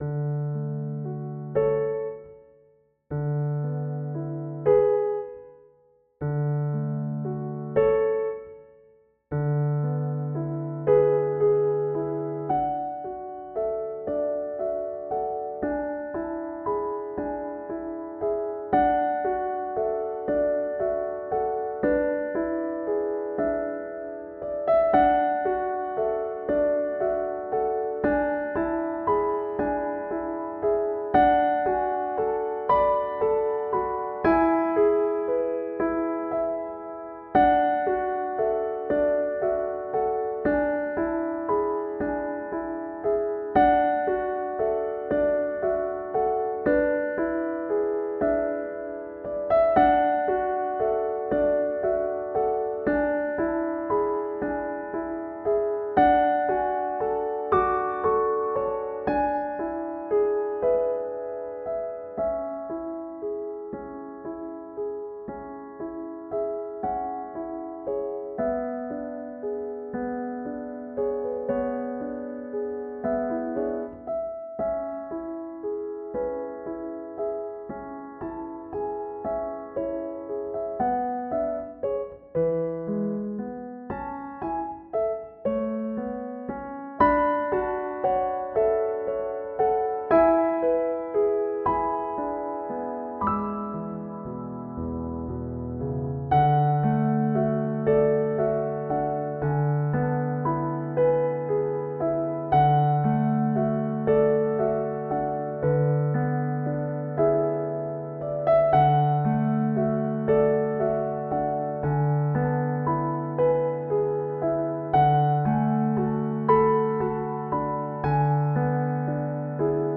フリーBGM素材
無機質な白い部屋にぽつんと置いてあるピアノとかそういうかんじ。